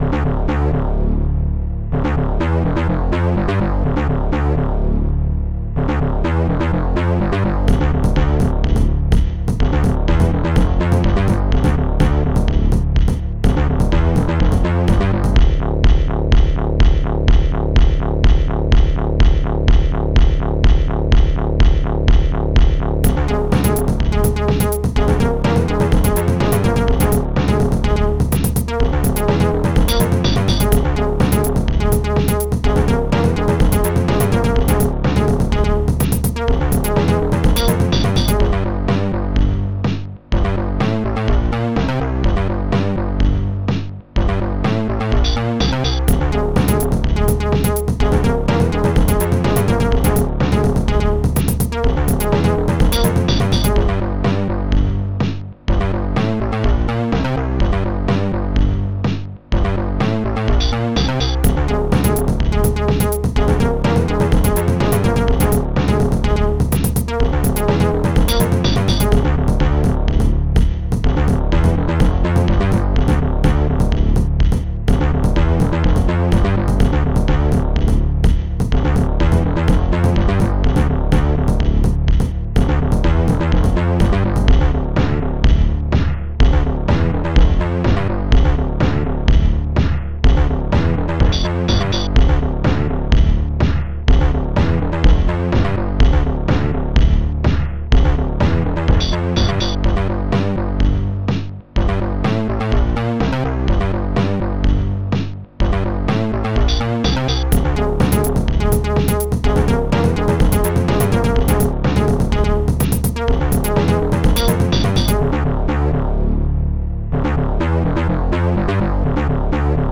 2 channels
Instruments monsterbass bassdrum3 funbass rubberbass wowbass sphere snare1 hihat1